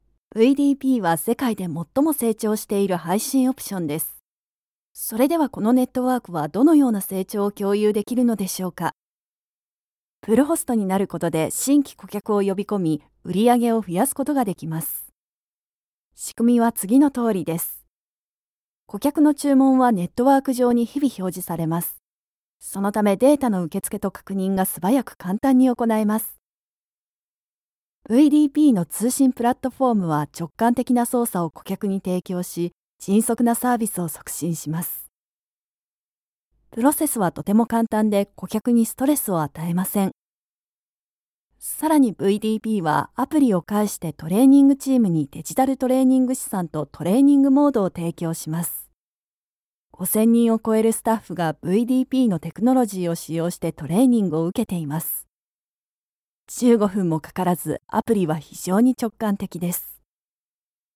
For those of you who want REAL Female JAPANESE voice over! Friendly, sweet, softspoken, believable, caring, warm and comfortable natural voice.
Sprechprobe: Industrie (Muttersprache):
Her voice can be natural, warm, friendly, inviting, yet approachable, suitable, sweet, playful, institutional and much more …!
Corporate-NoBGM.mp3